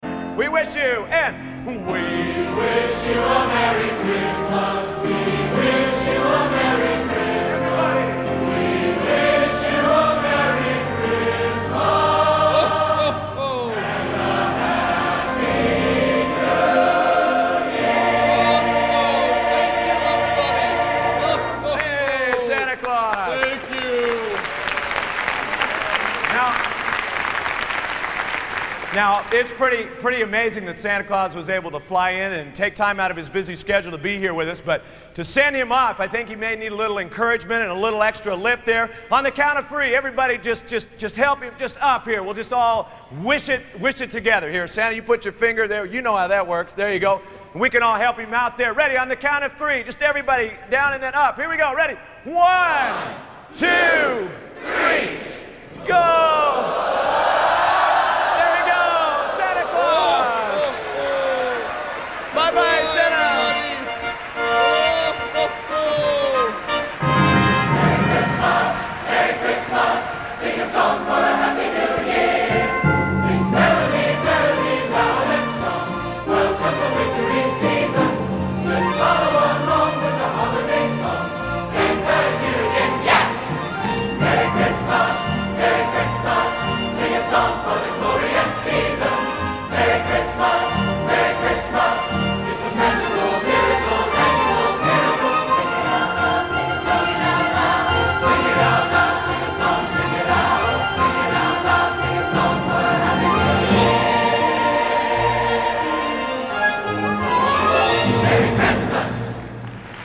Location: West Lafayette, Indiana
Genre: | Type: Christmas Show |